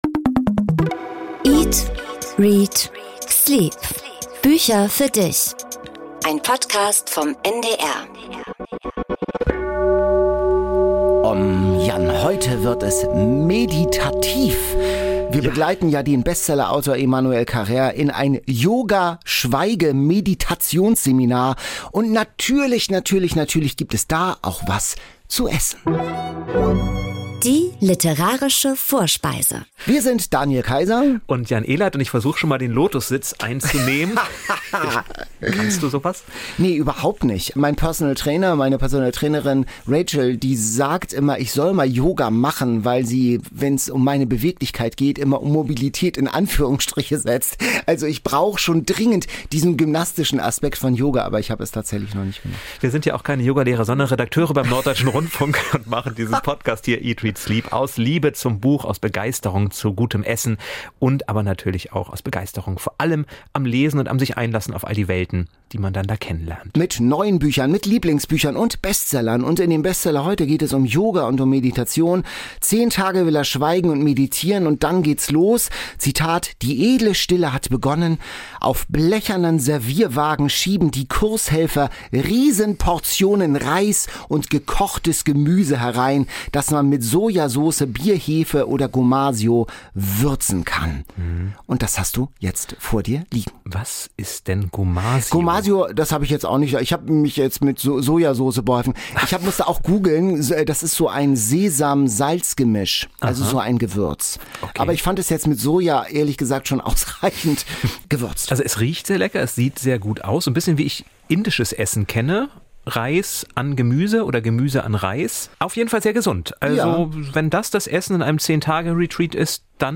Zu Gast ist diesmal der Thriller-Autor John Grisham. Er begrüßt die beiden Hosts von seiner Farm in Virginia.